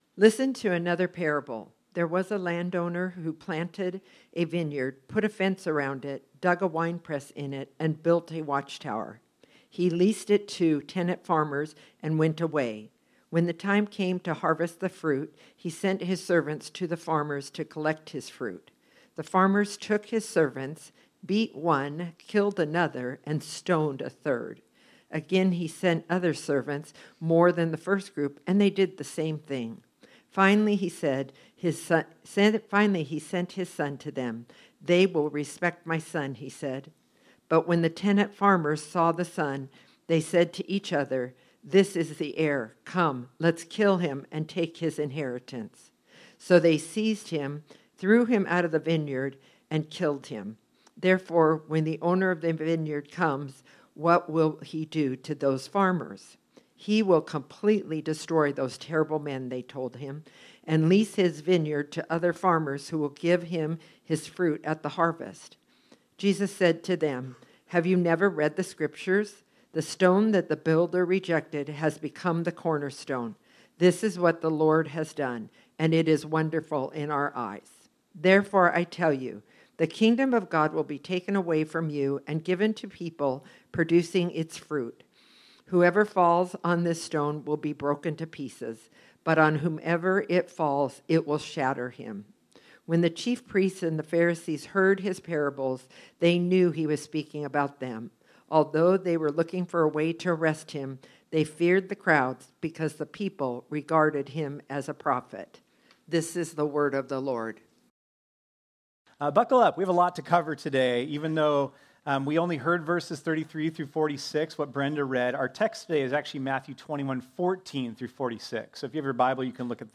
This sermon was originally preached on Sunday, September 29, 2024.